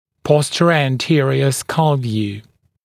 [ˌpɔstərəuæn’tɪərɪə skʌl vjuː][ˌпостэроуэн’тиэриэ скал вйу:]рентгенография черепа в затылочной проекции